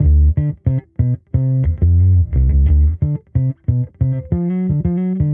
Index of /musicradar/sampled-funk-soul-samples/90bpm/Bass
SSF_PBassProc1_90D.wav